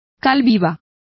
Complete with pronunciation of the translation of quicklime.